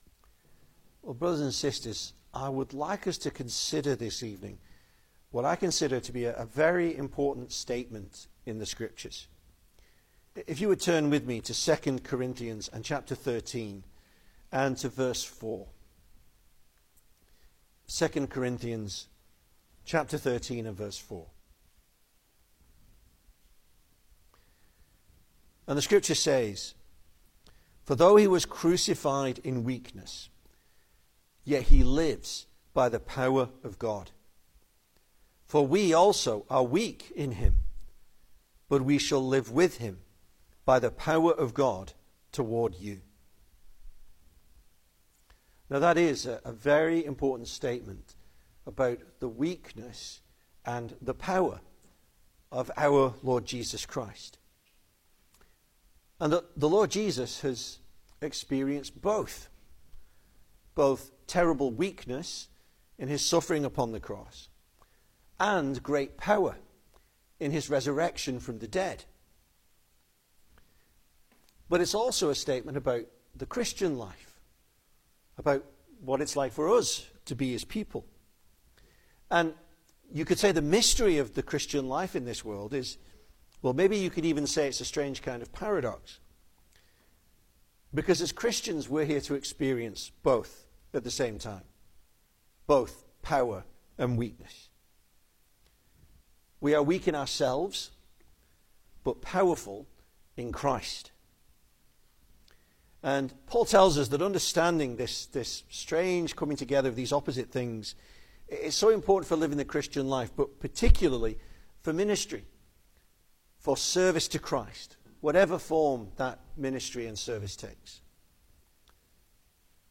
Service Type: Sunday Evening
Easter Sermons